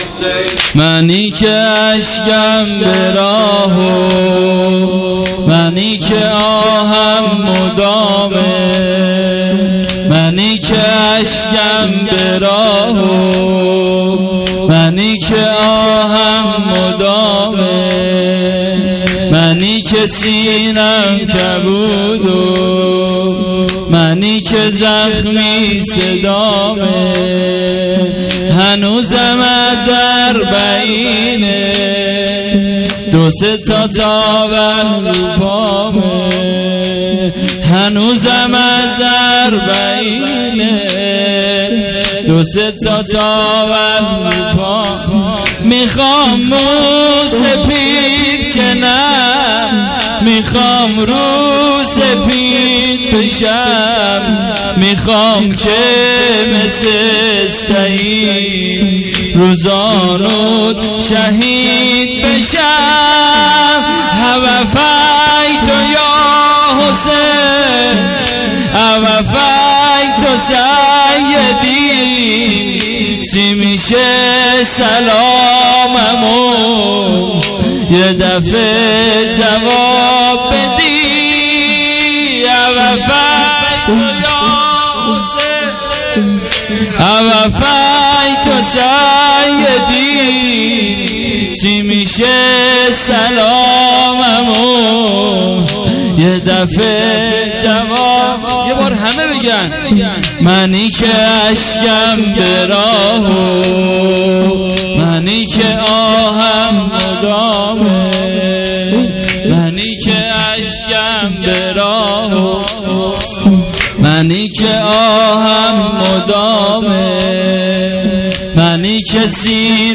شب چهارم محرم الحرام 1399